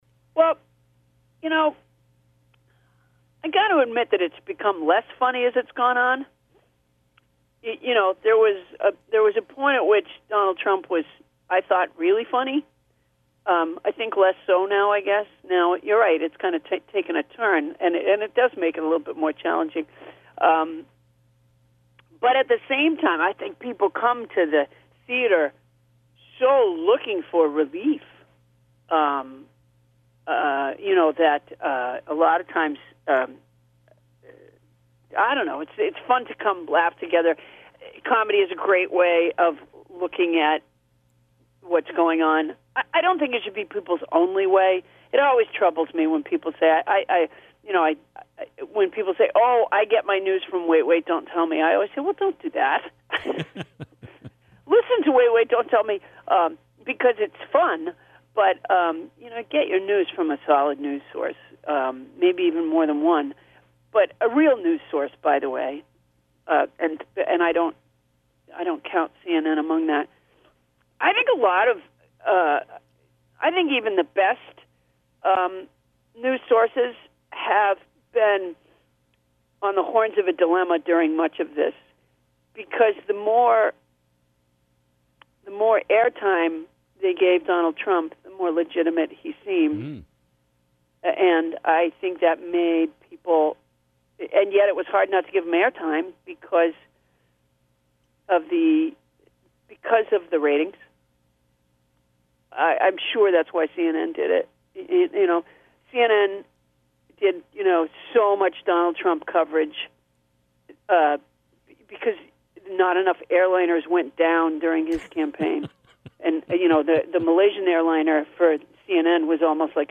Part two of our very fun conversation with comedian Paula Poundstone. Paula takes much of the second half of the interview to weigh in on this year’s election cycle and some of the media outlets covering it. https